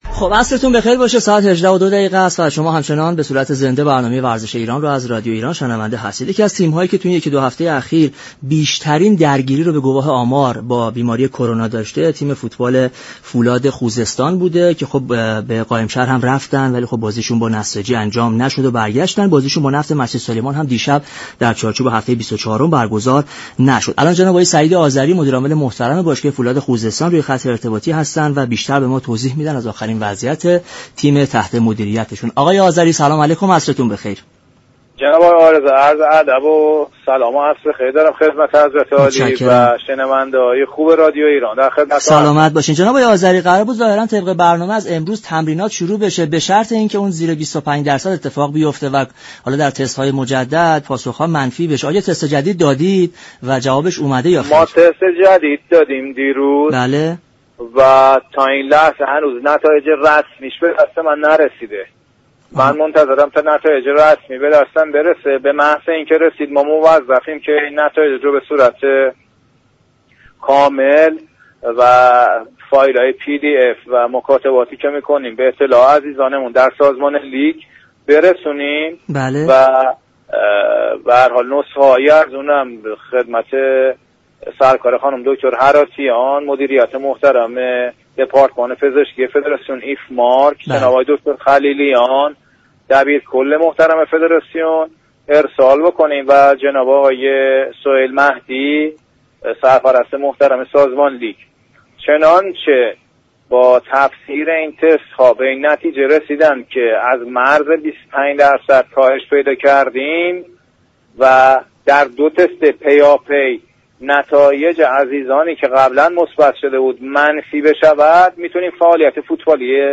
در گفتگو با برنامه «ورزش ایران» رادیو ایران